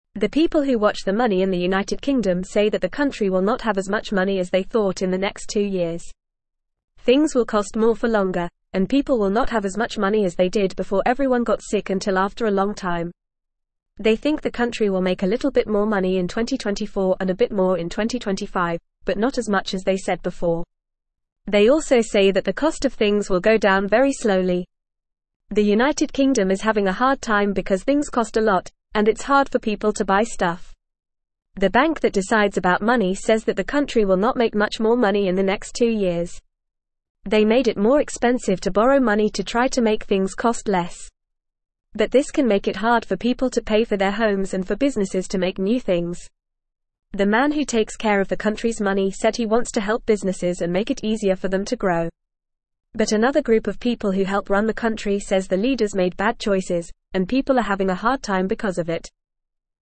Fast
English-Newsroom-Beginner-FAST-Reading-UK-Economy-Faces-Challenges-Less-Money-Higher-Costs.mp3